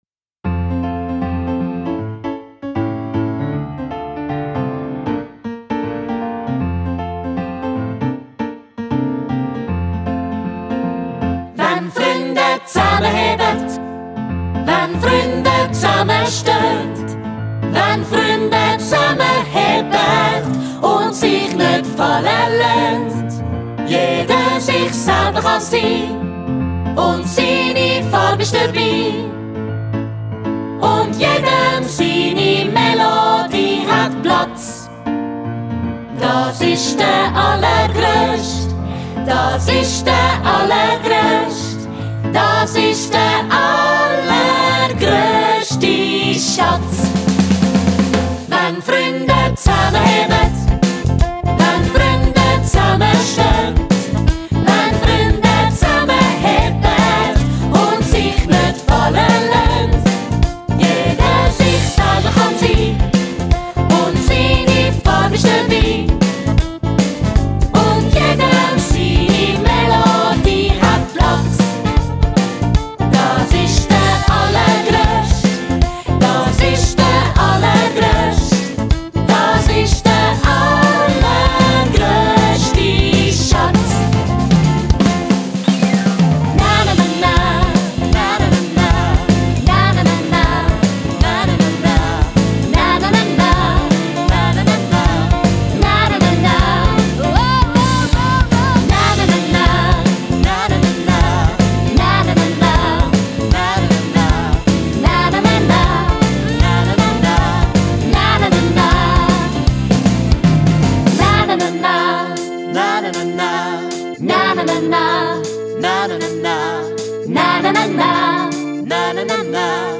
aufgestellte Tanzgrooves und hitverdächtige Songs
Mitmach- und Mitsing-Musical
Das Musical verbreitet gute Laune – von Anfang bis Schluss.